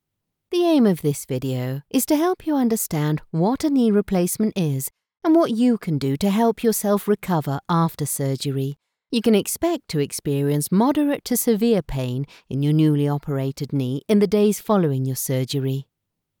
Female
Explainer Videos
Medical Compassionate
0411KneeSurgery_Medical_Compassionate.mp3